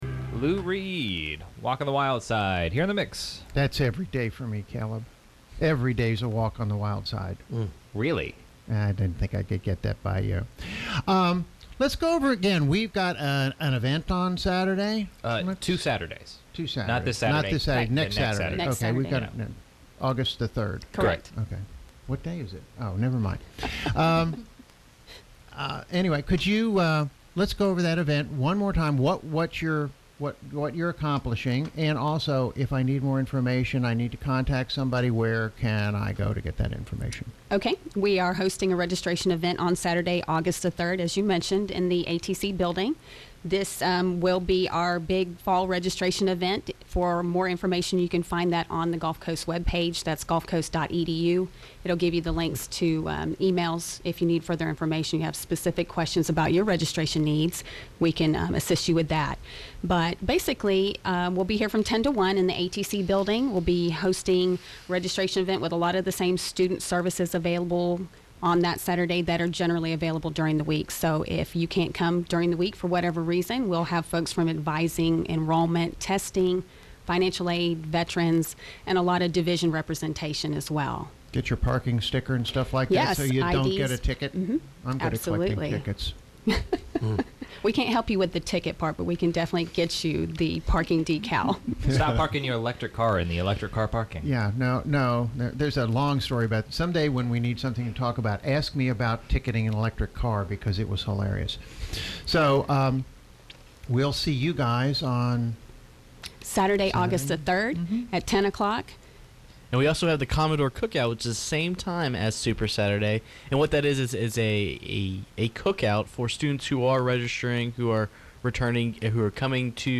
WKGC Studio – The show was packed this morning on The Morning Mix with guest from the college to talk about an event coming up called Super Saturday at Gulf Coast State College?